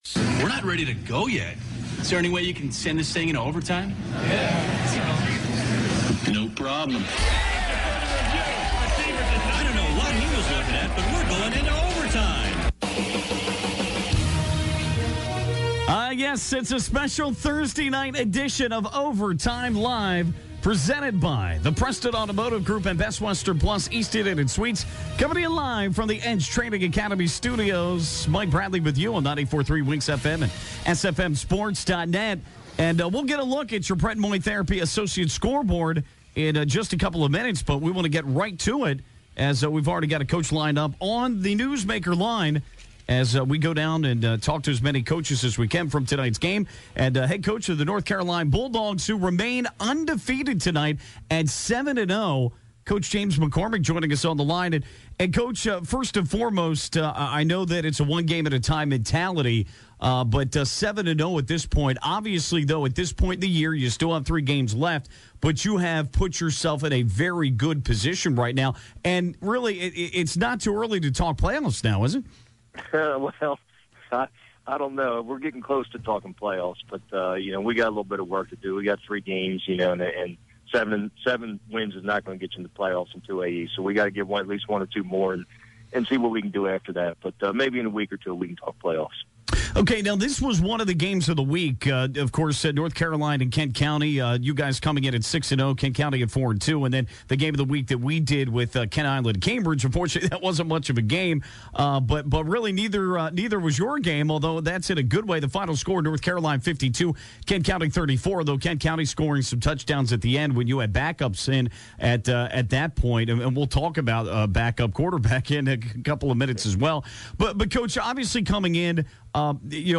10/15/15: Overtime Live